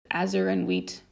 A segment of the "Spokent Text" audio file, specifically the phrase "azure and wheat". No further effects were added. This sound is correlated with the letter "v" on the computer keyboard.
Edited with and exported from Abletone Live.